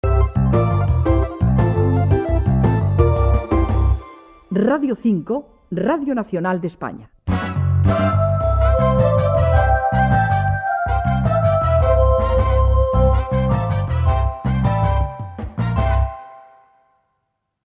Identificació i sintonia de l'emissora